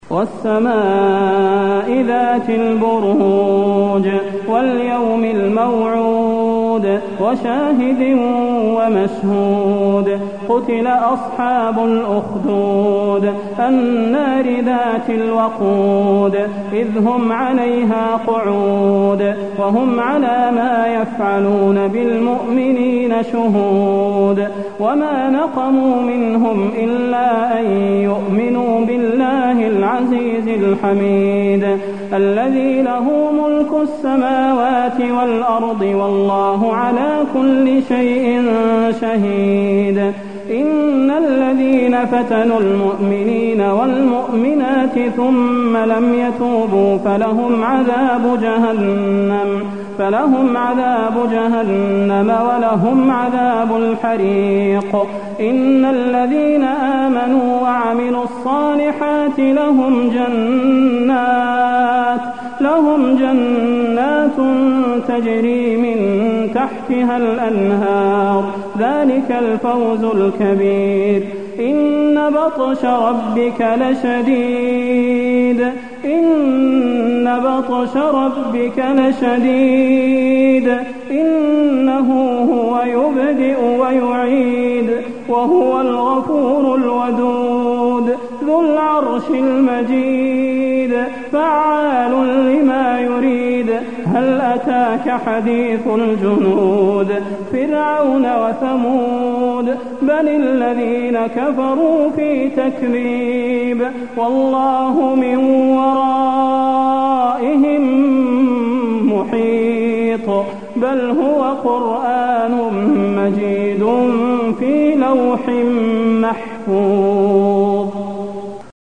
المكان: المسجد النبوي البروج